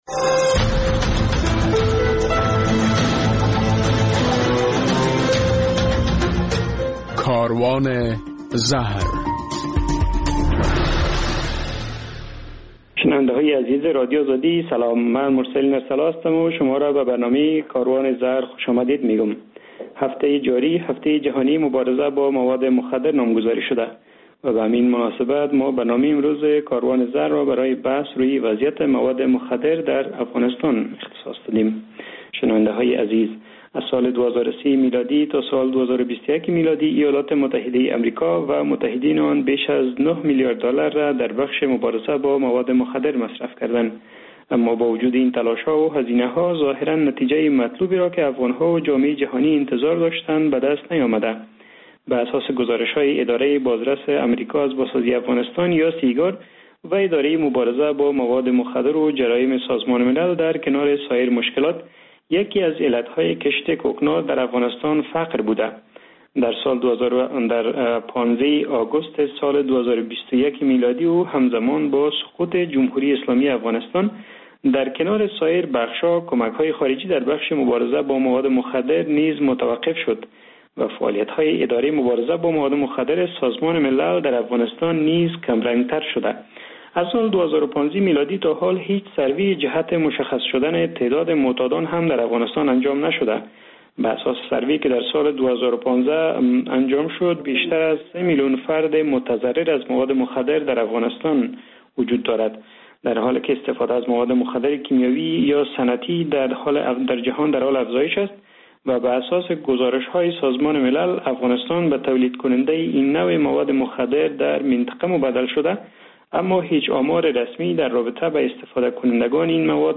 هفته جاری هفته جهانی مبارزه با مواد مخدر است و در این برنامه کاروان زهر در یک بحث با مهمانان وضعیت کشت، تولید، قاچاق و استفاده از مواد مخدر به بحث گرفته شده است.